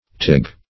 Meaning of tiewig. tiewig synonyms, pronunciation, spelling and more from Free Dictionary.
Search Result for " tiewig" : The Collaborative International Dictionary of English v.0.48: Tiewig \Tie"wig`\, n. A wig having a tie or ties, or one having some of the curls tied up; also, a wig tied upon the head.